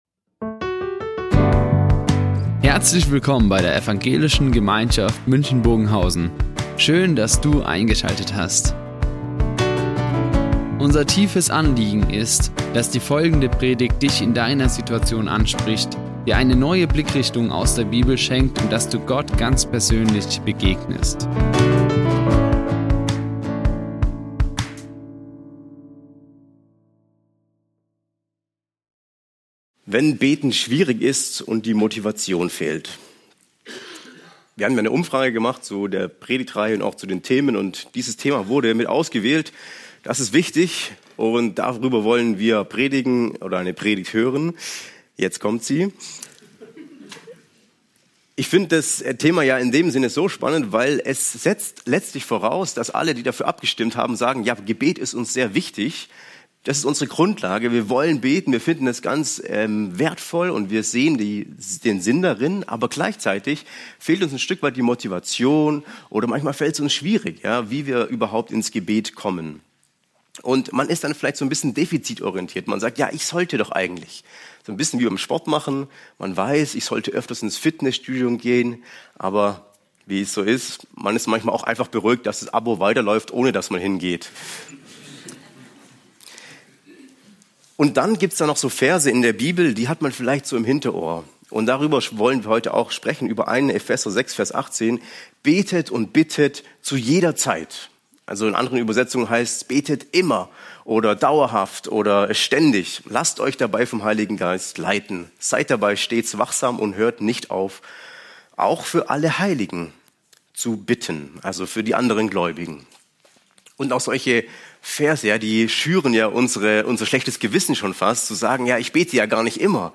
Der Dreieinige Plan | Predigt Galater 4, 4-6
Die Aufzeichnung erfolgte im Rahmen eines Livestreams.